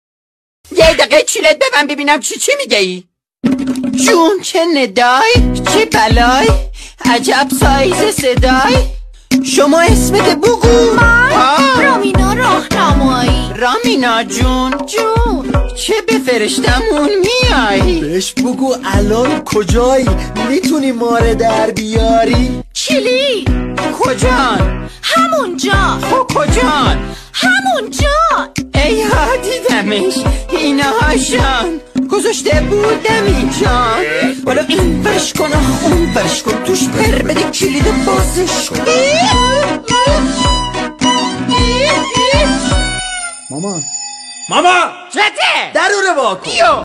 ریمیکس